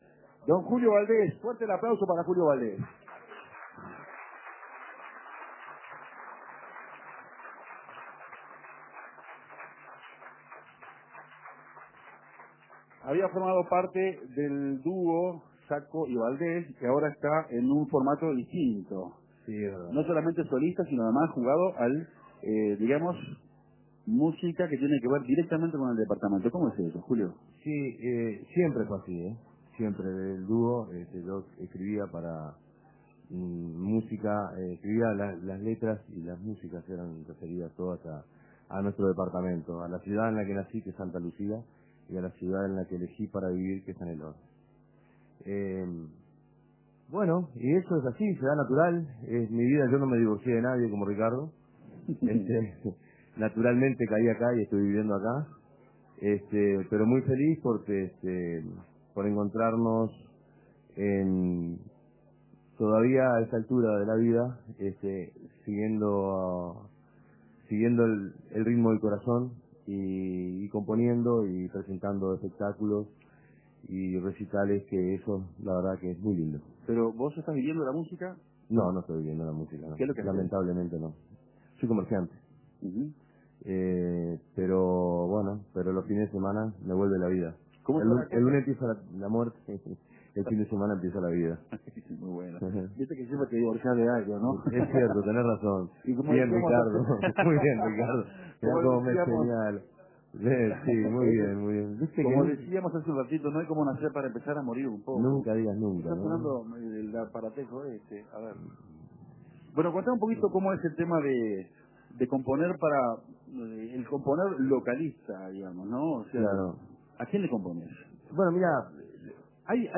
El cantante fue parte de la emisión desde Canelones